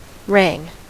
Ääntäminen
Ääntäminen US Tuntematon aksentti: IPA : /ɹæŋ/ Haettu sana löytyi näillä lähdekielillä: englanti Käännöksiä ei löytynyt valitulle kohdekielelle. Wrang on sanan wring imperfekti.